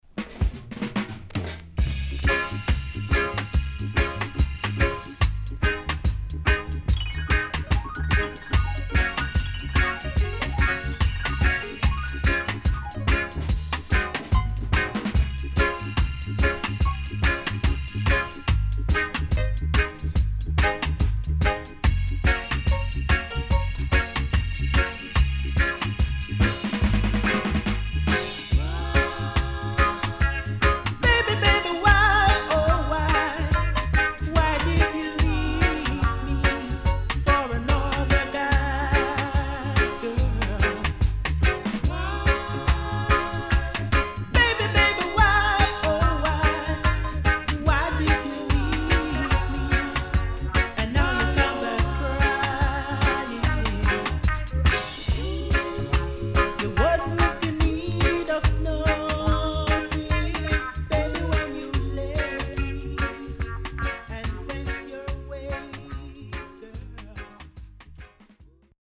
HOME > Back Order [VINTAGE LP]  >  STEPPER